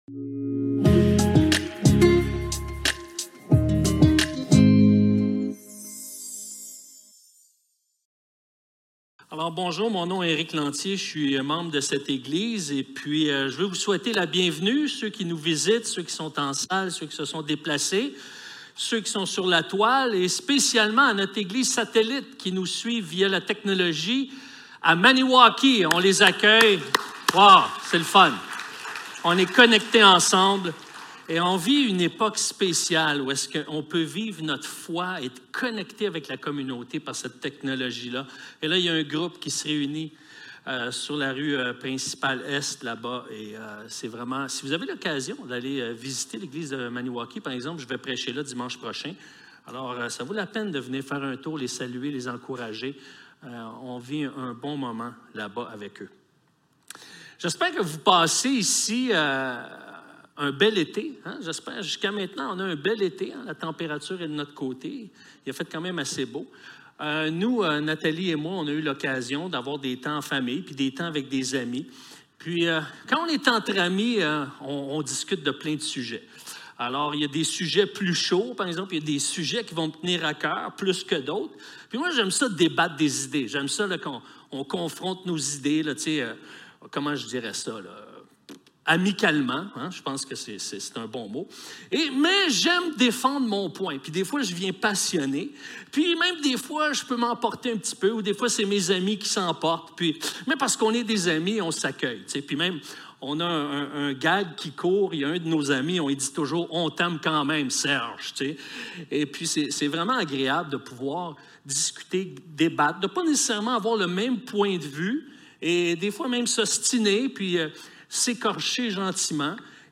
Actes 26.1-32 Service Type: Célébration dimanche matin Envoyés #38 Paul a saisi l'occasion de témoigner de sa foi avec sagesse